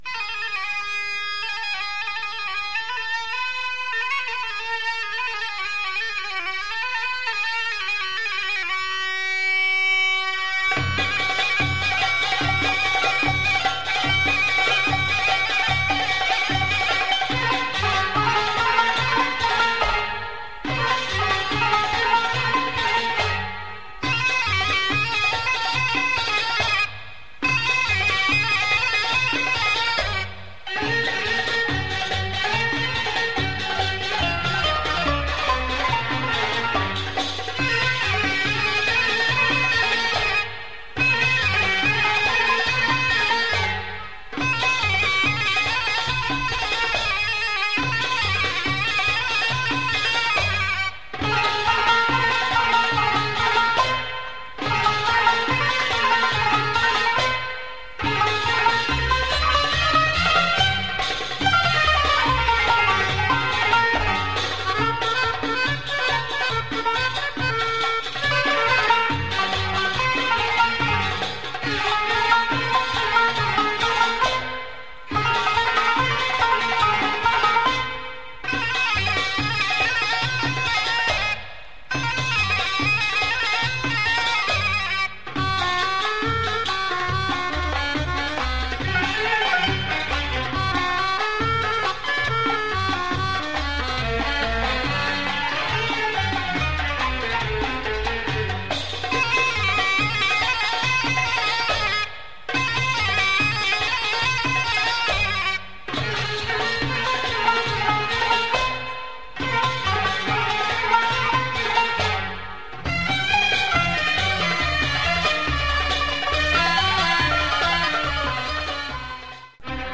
Lebanese musical